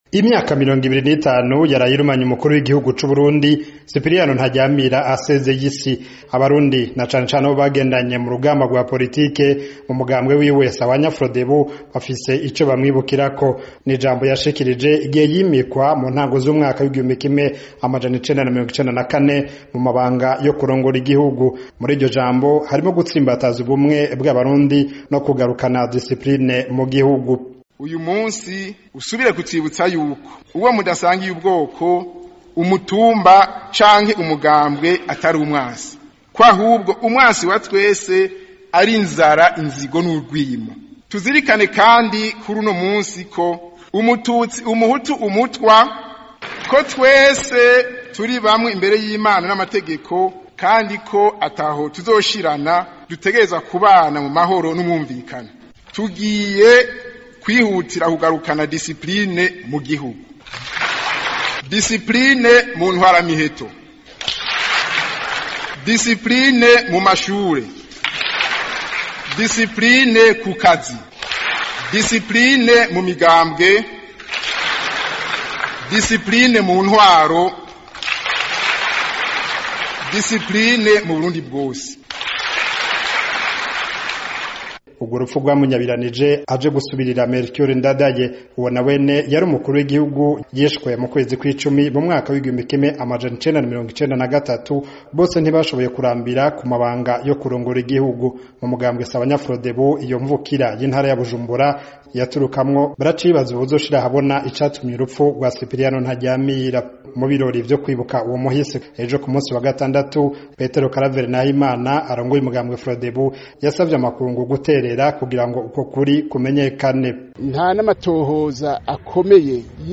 Umumenyeshamakuru w'Ijwi ry'Amerika uri i Kigali